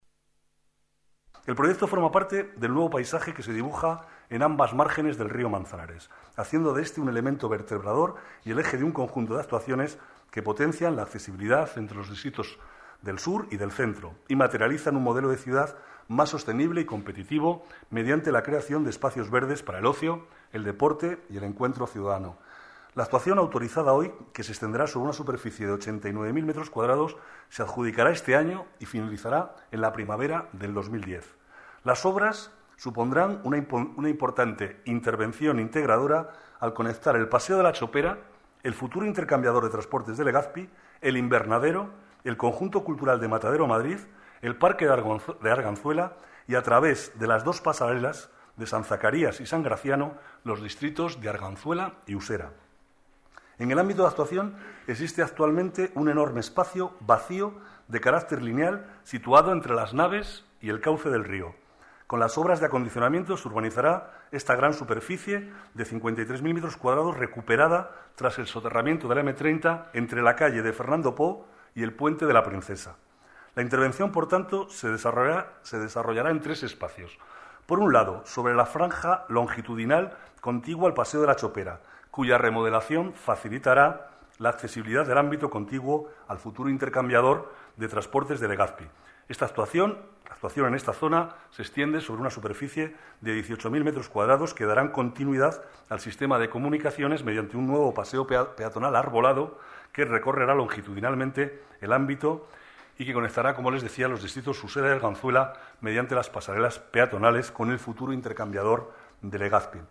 Nueva ventana:Declaraciones del vicealcalde Manuel Cobo